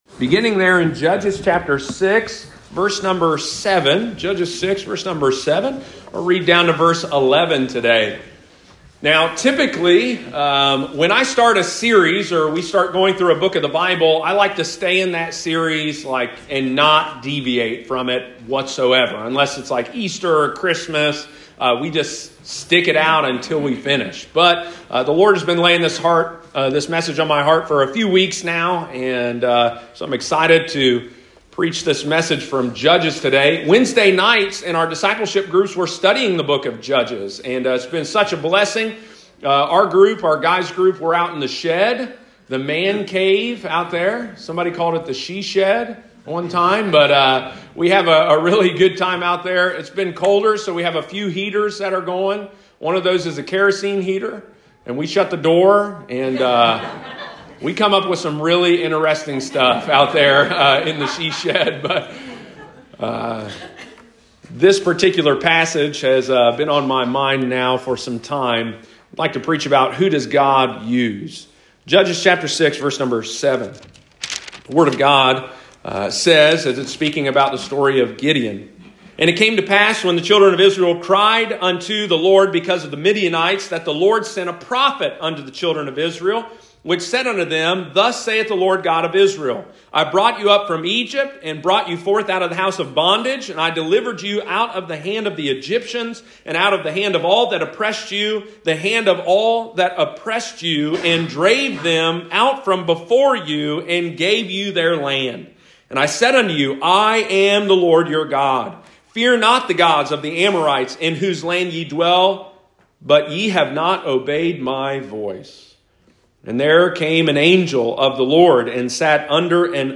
Speaker